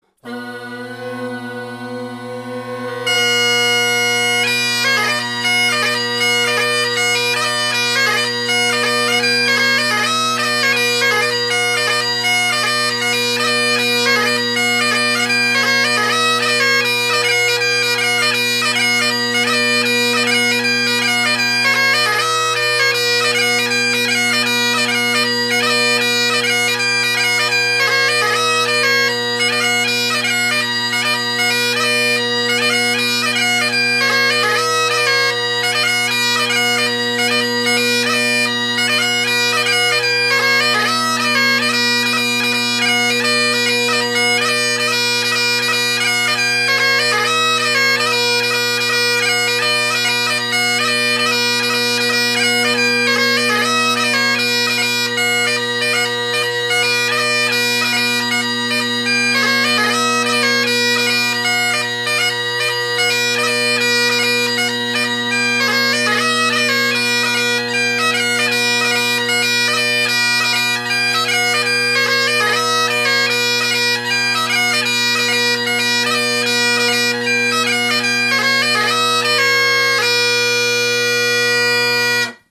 Below are several recordings made over several days of most of my collection of bagpipes.
Jacky Latin (Gellaitry + original Kinnaird tenors and regular X-TREME bass, Colin Kyo + Sound Supreme reed) – mic off to the left (recorded 2019-08-15)